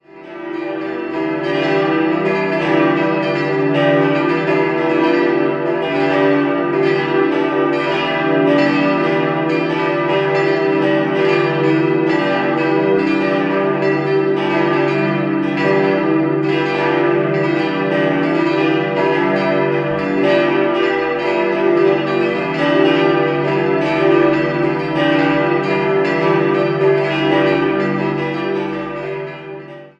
Wegen schwerer Kriegsbeschädigungen war ein Neuaufbau von 1955 bis 1957 notwendig. 6-stimmiges Geläut: d'-e'-g'-a'-h'-d'' Alle Glocken stammen aus der Gießerei Bachert und wurden im Jahr 1959 (6-3) und 1965 (2+1) gegossen, Nr. 3 in Dur-Rippe.